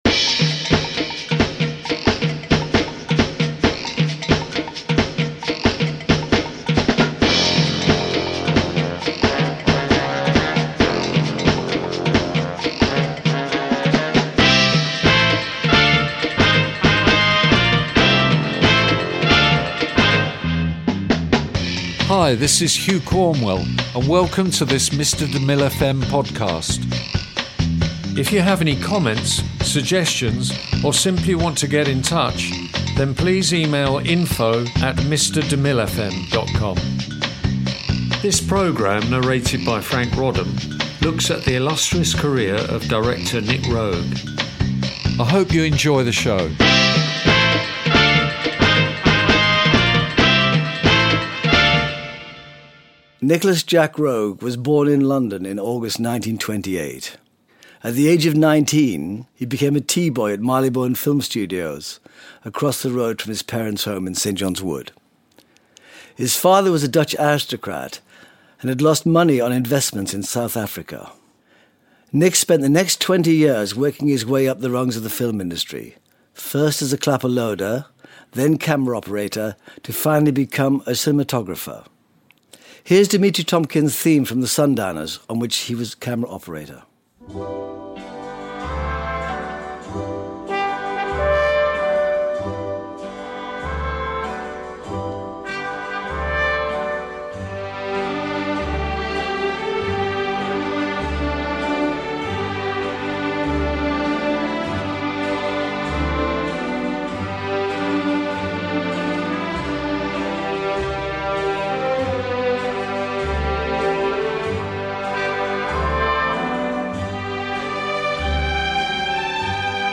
This program, narrated by Franc Roddam, looks at the illustrious career of director, Nic Roeg.